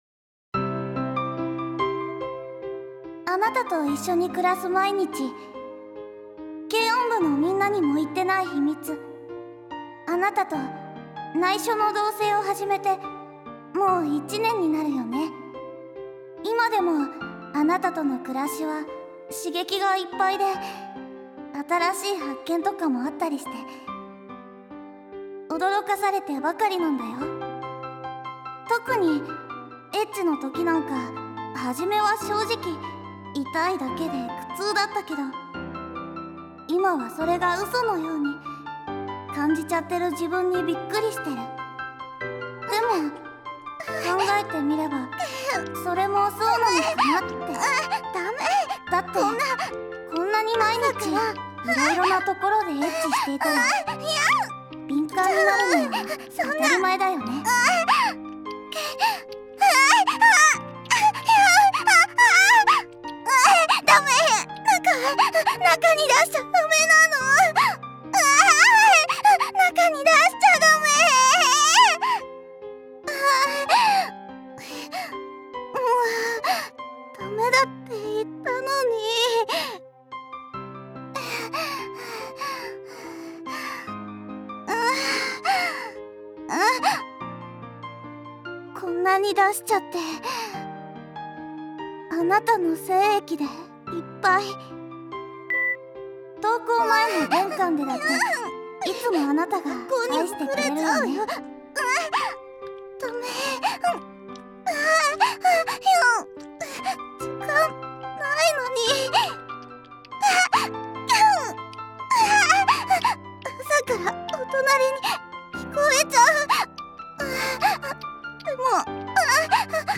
マンガをボイス付きで楽しめるよ！